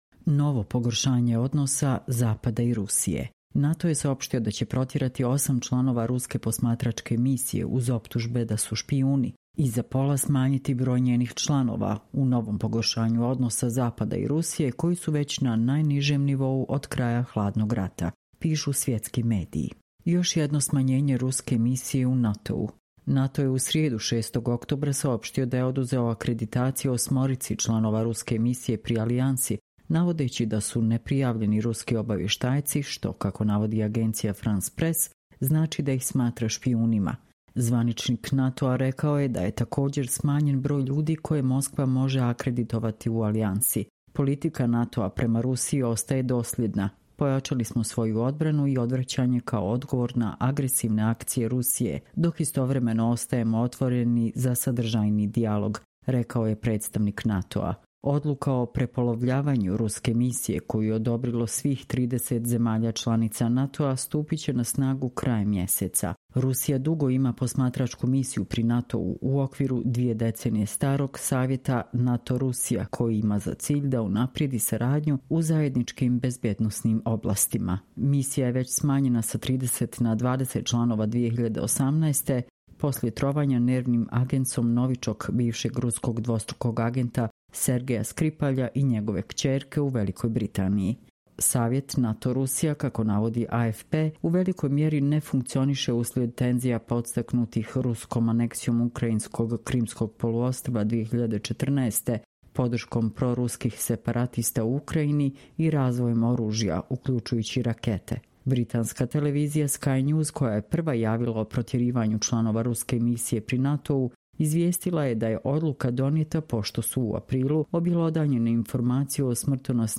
Čitamo vam: Novo pogoršanje odnosa Zapada i Rusije